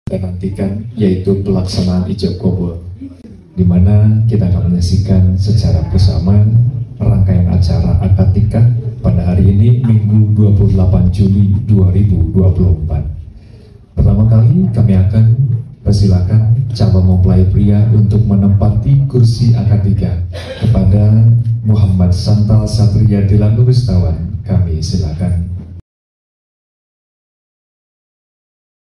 Contoh Bridging MC sebelum Akad sound effects free download